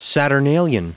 Prononciation du mot saturnalian en anglais (fichier audio)
saturnalian.wav